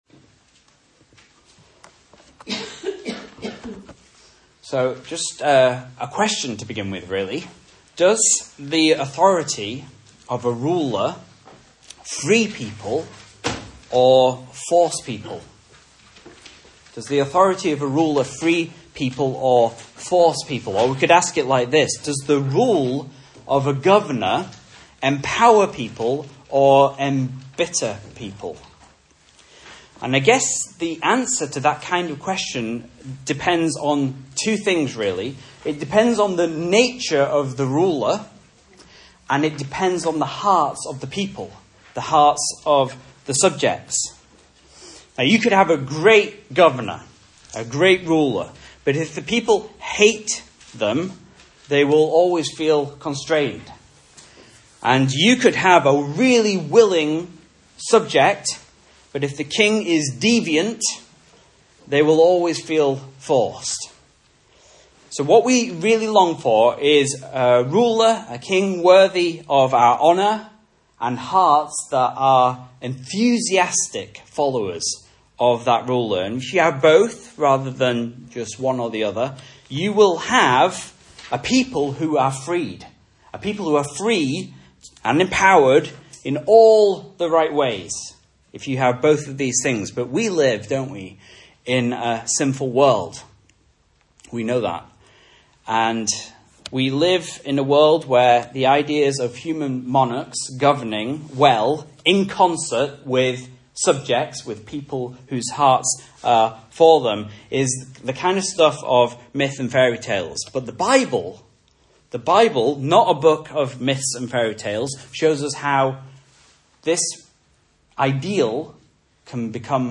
Message Scripture: Mark 12:35-44 | Listen